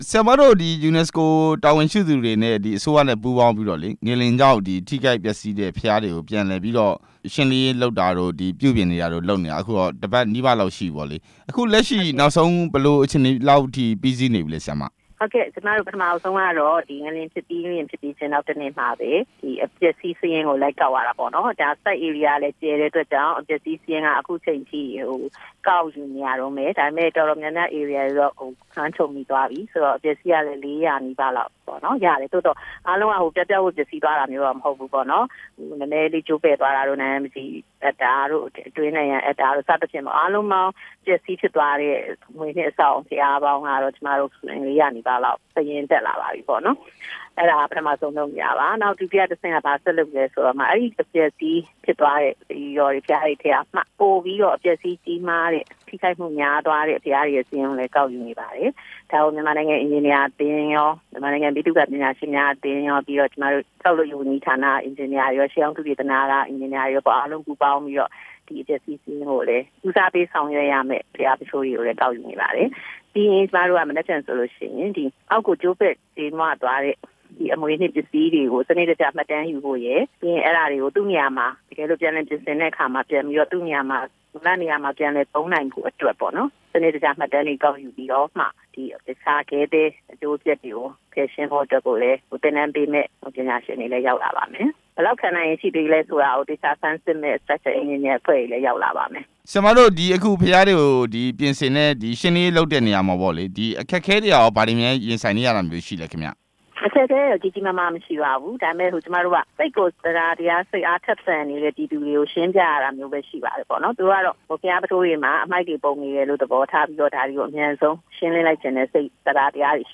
ငလျင်ကြောင့် ပျက်စီးမှုအခြေအနေအကြောင်း မေးမြန်းချက်